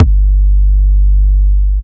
Index of /Antidote Advent/Drums - 808 Kicks
808 Kicks 03 G.wav